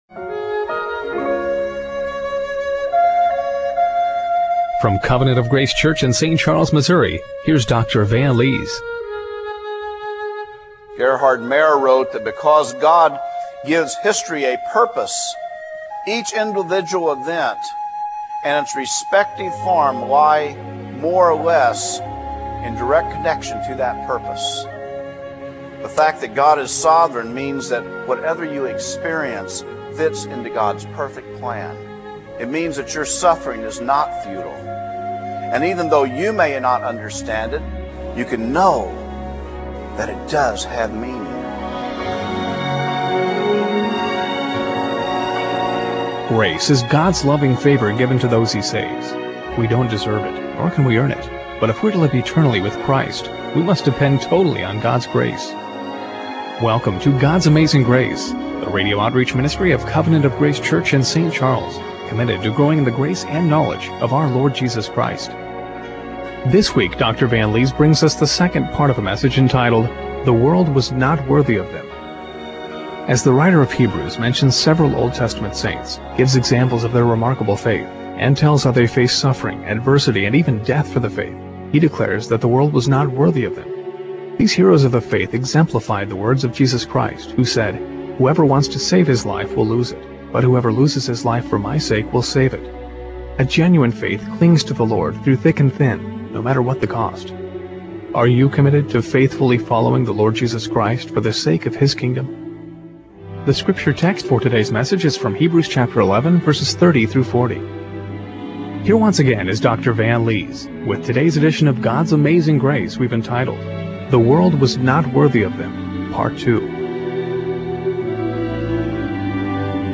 Hebrews 11:30-40 Service Type: Radio Broadcast Are you committed to faithfully following the Lord for the sake of His Kingdom?